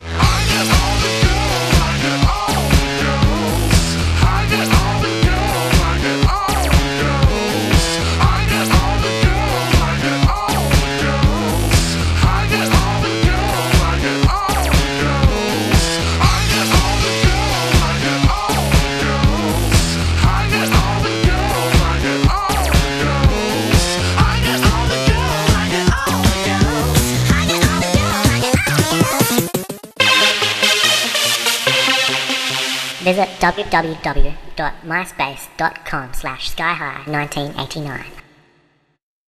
dance/electronic
House